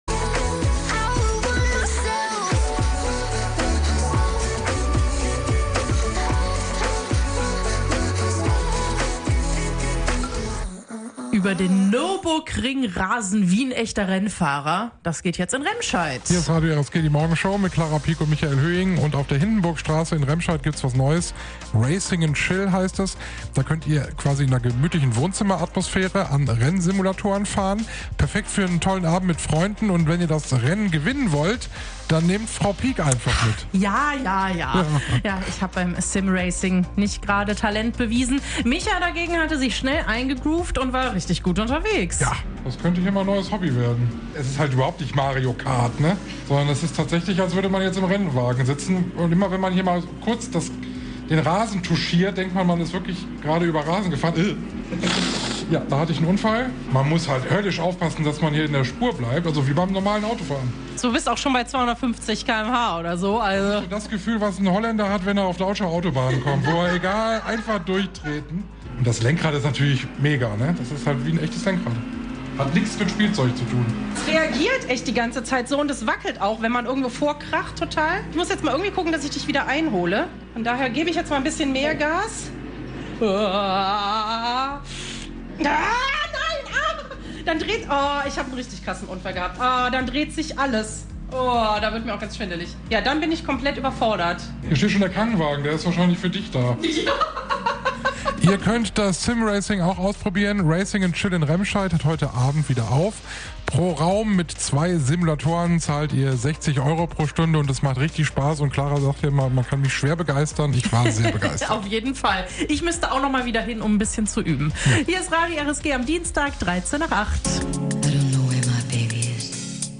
Unsere Moderatoren haben die E-Racing Simulatoren für euch getestet.